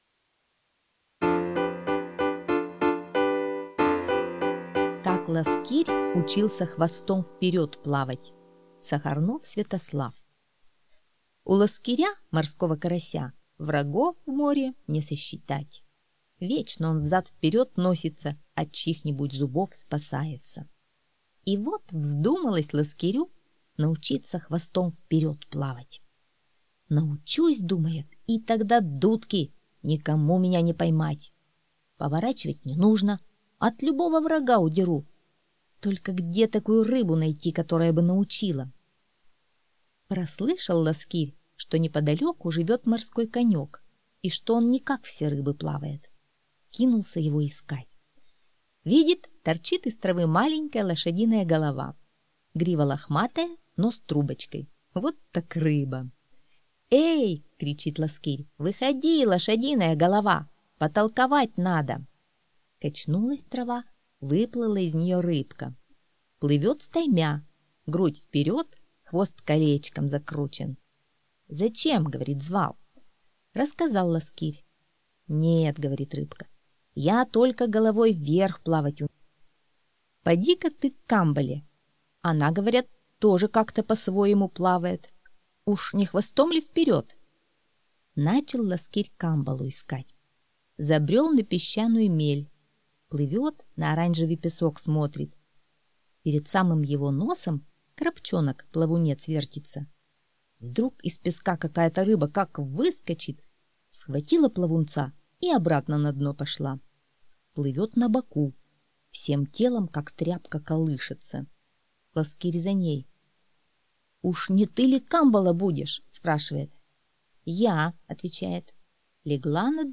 Как ласкирь учился хвостом веред плавать — аудиосказка Сахарнова С.В. Слушать сказку онлайн на сайте Мишкины книжки.